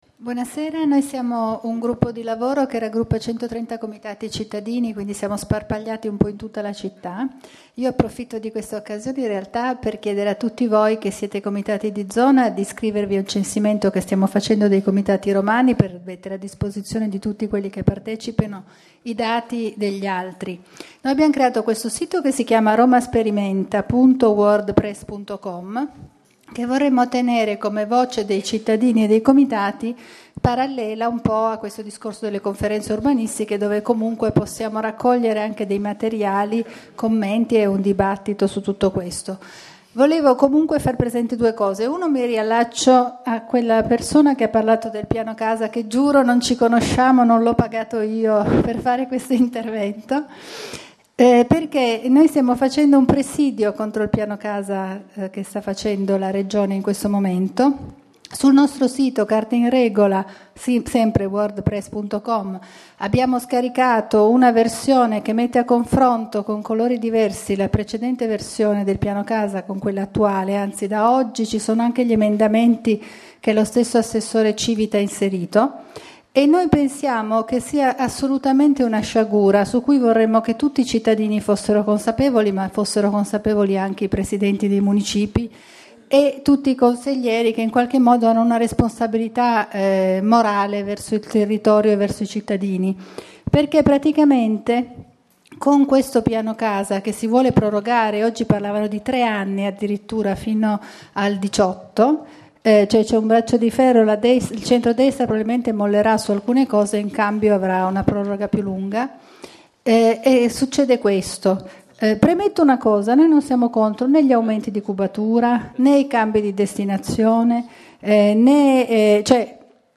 Registrazione integrale dell'incontro svoltosi il 16 ottobre 2014 presso la sala consiliare del Municipio XIII, in Via Aurelia, 474.